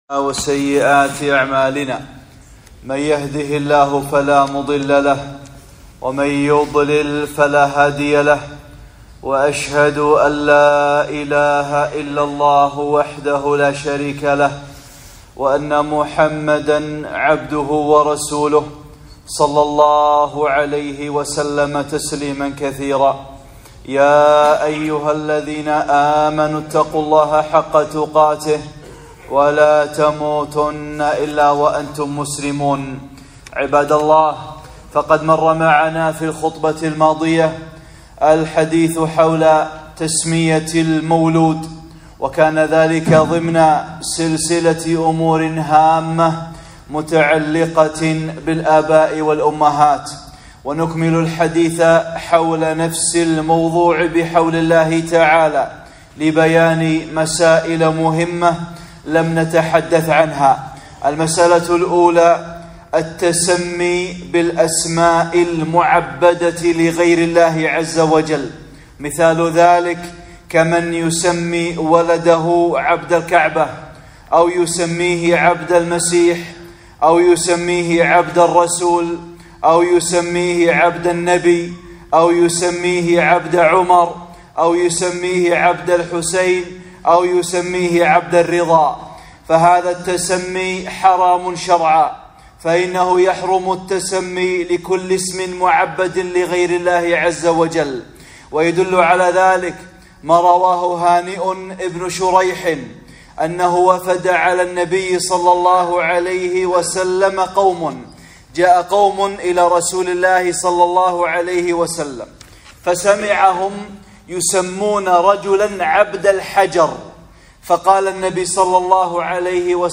(16) خطبة - الأسماء الممنوعة - أمور هامة متعلقة بالآباء والأمهات